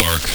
BARK.